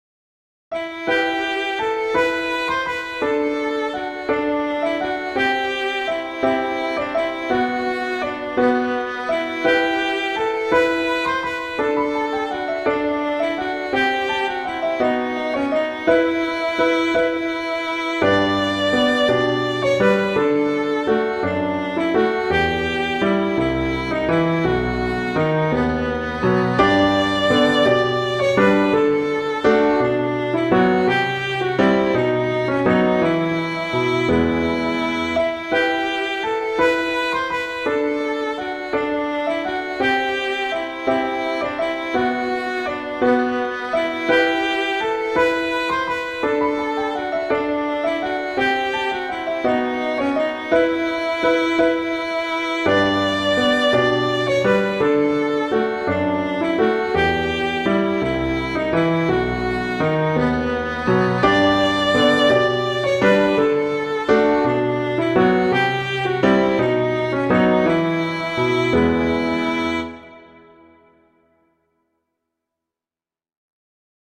violin and piano